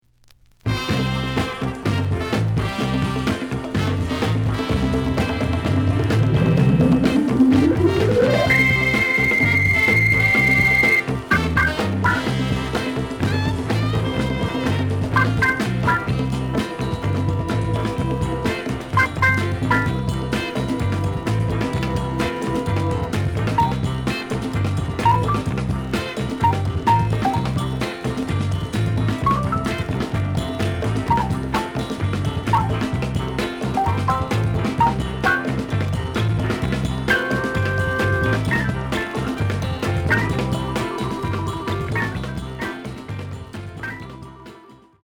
The audio sample is recorded from the actual item.
●Genre: Funk, 60's Funk